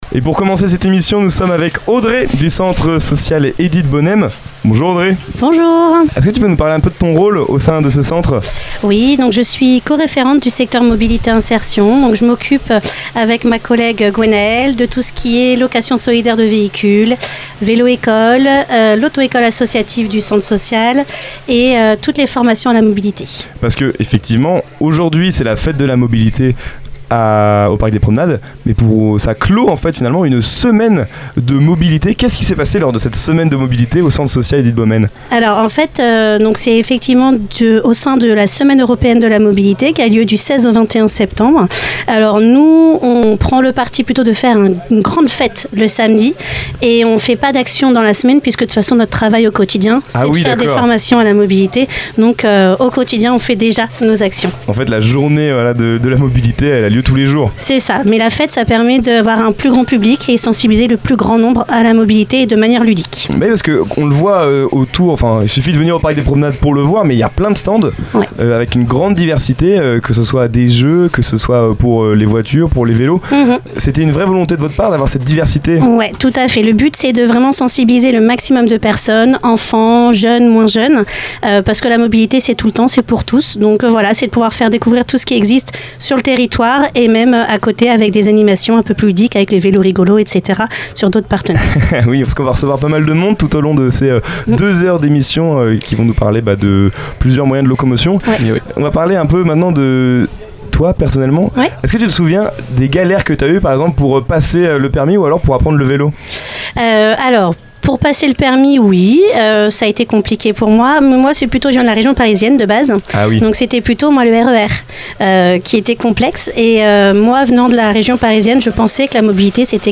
À l’occasion de la Fête de la Mobilité organisée au Parc des Promenades à Alençon, nous étions en direct depuis la Mystery Machine (studio radio aménagé dans une camionnette).